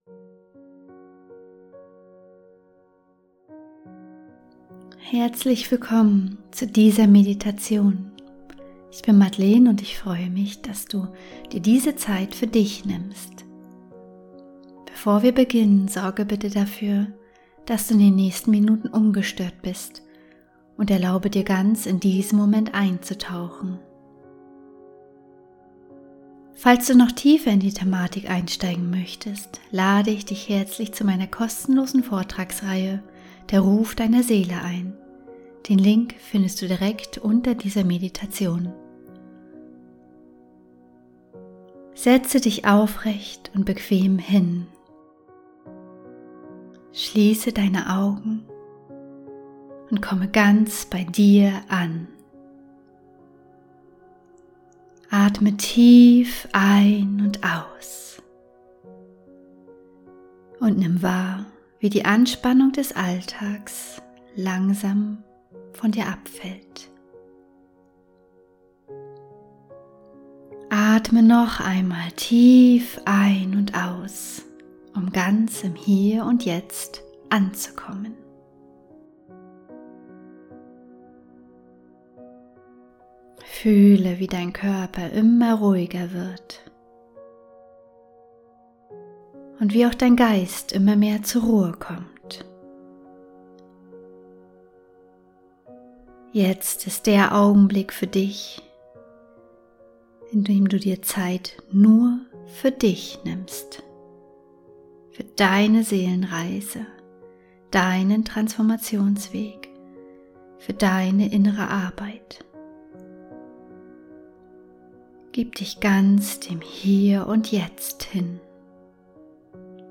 Willkommen zu dieser 20-minütigen geführten Meditation zum Loslassen der Zukunftsillusion.